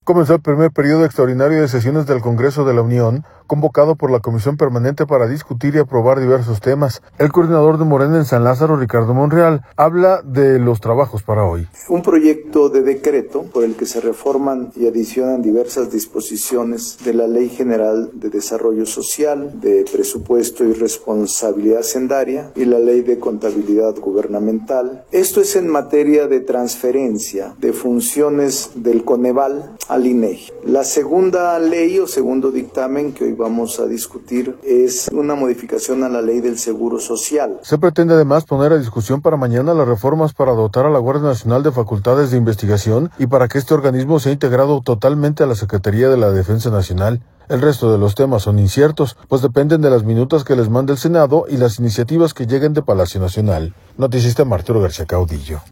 audio Comenzó el primer periodo extraordinario de sesiones del Congreso de la Unión, convocado por la Comisión Permanente para discutir y aprobar diversos temas. El coordinador de Morena en San Lázaro, Ricardo Monreal habla de los trabajos para hoy.